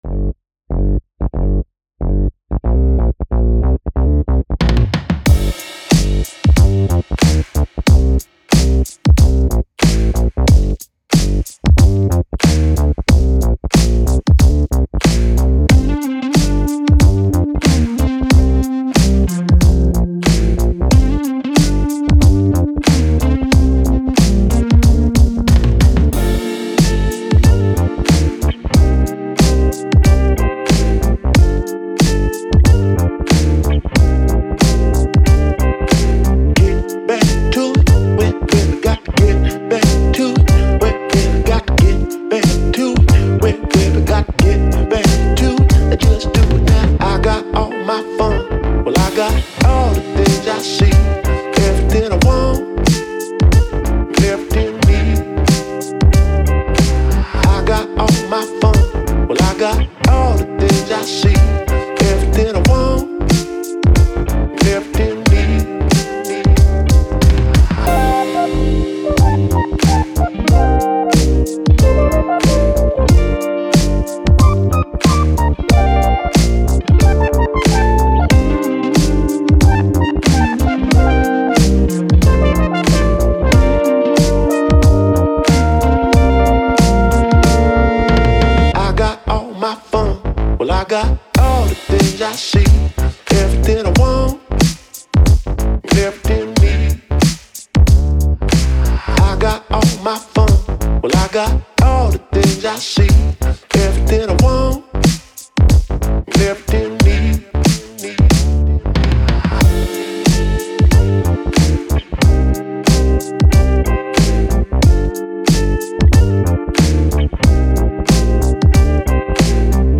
Funk, Dance, Vibe, Cool, Cheeky, Vocal